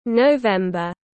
Tháng 11 tiếng anh gọi là november, phiên âm tiếng anh đọc là /nəʊˈvem.bər/
November /nəʊˈvem.bər/